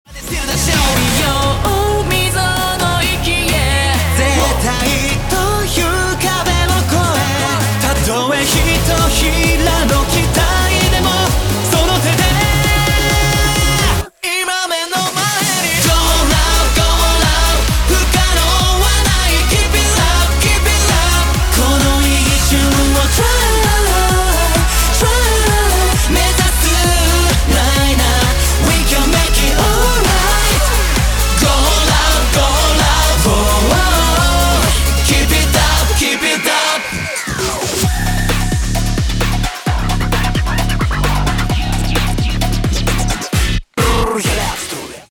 “攻め“のダンスナンバー